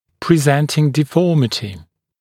[prɪ’zentɪŋ dɪ’fɔːmɪtɪ][при’зэнтин ди’фо:мити]проявившийся дефект, имеющаяся деформация